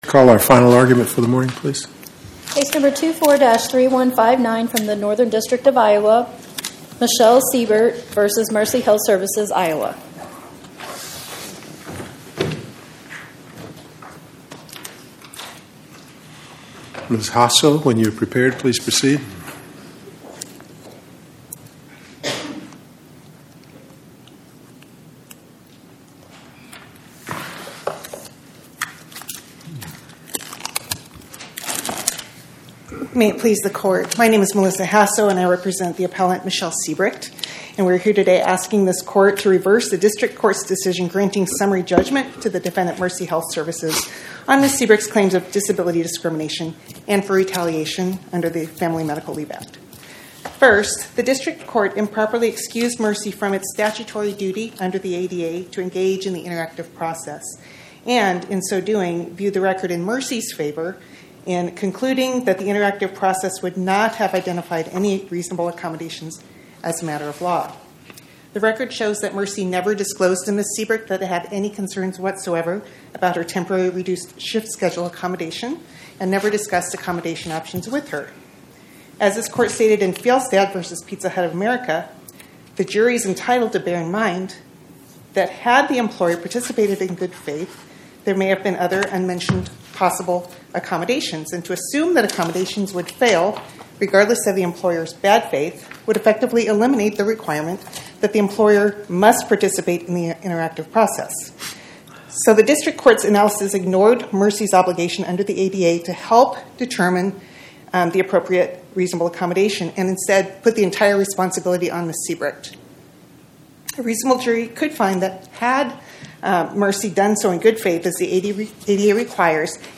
Podcast: Oral Arguments from the Eighth Circuit U.S. Court of Appeals Published On: Tue Sep 16 2025 Description: Oral argument argued before the Eighth Circuit U.S. Court of Appeals on or about 09/16/2025